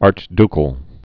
(ärch-dkəl, -dy-)